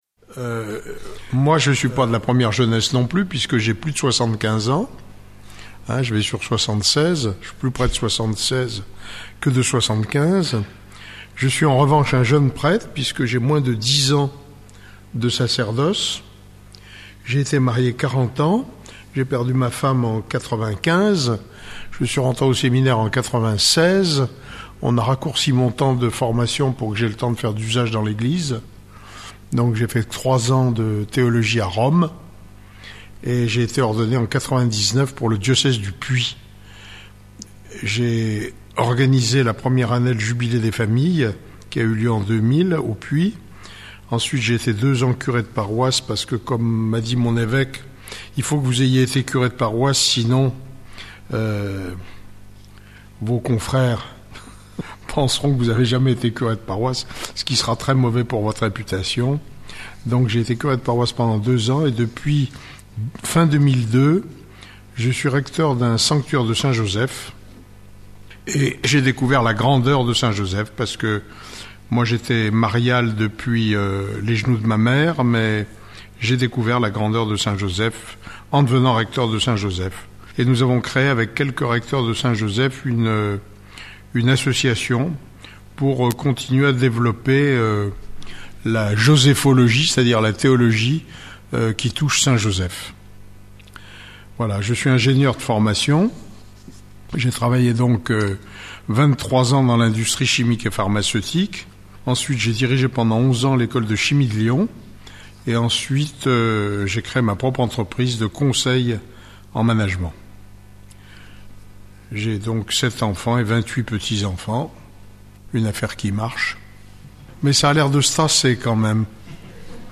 Enseignement
Enregistré à Nouan le Fuzelier au cours d'une récollection pour tous du 20 au 22 février 2009.
Format :MP3 64Kbps Mono